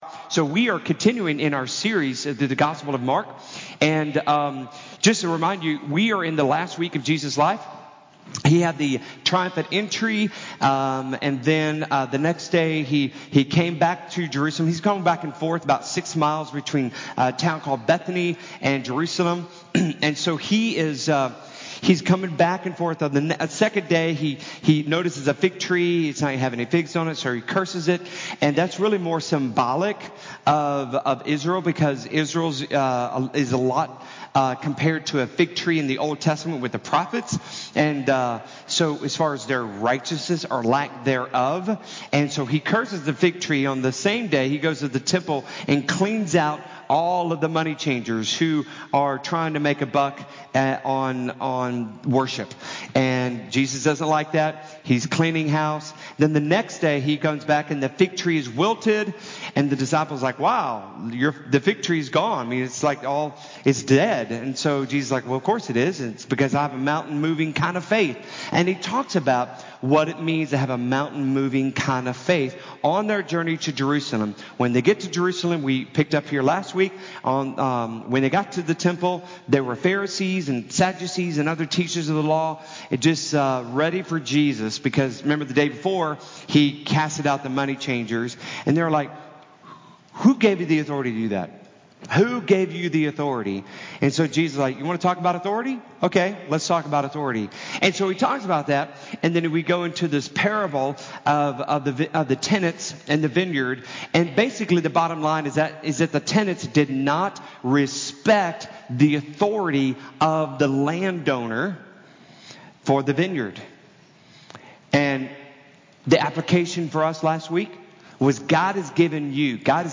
Sermon Audio
Paying-Government-and-God-Sermon-Audio-CD.mp3